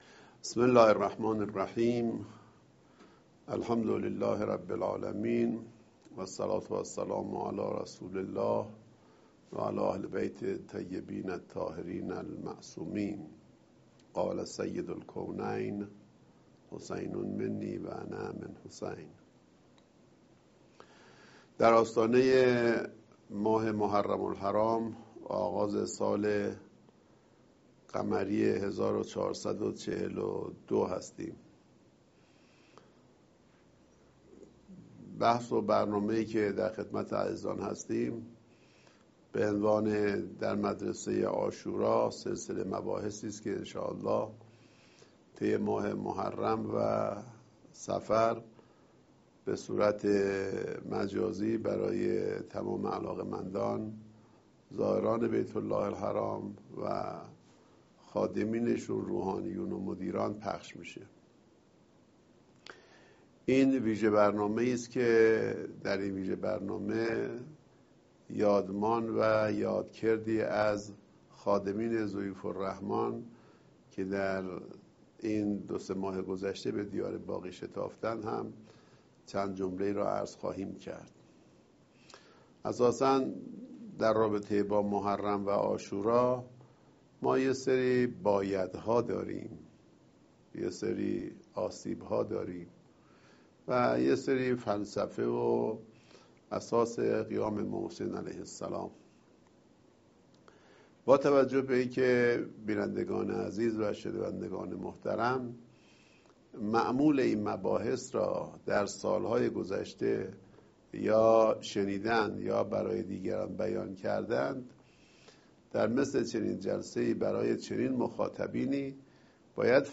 سخنان نمایندۀ محترم ولی فقیه در اولین جلسۀ سلسله برنامه‌های «در مدرسۀ عاشورا»